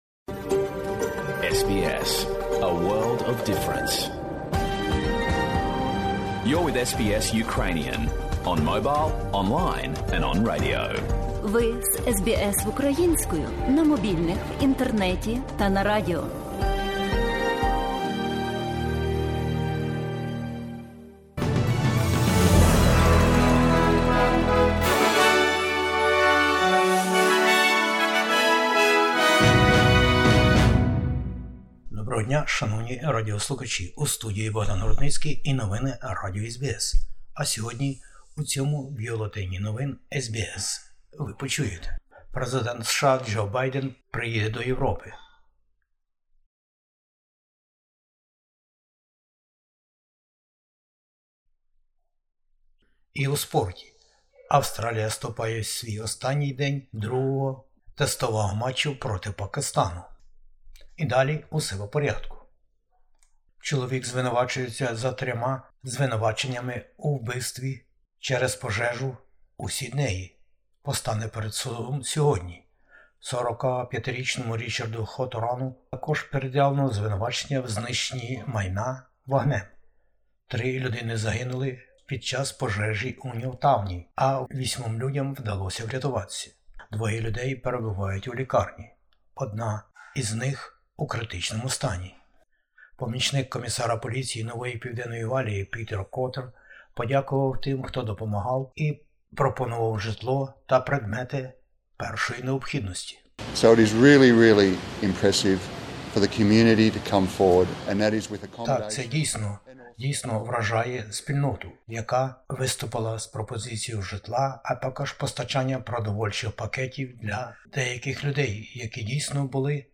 Бюлетень новин SBS українською.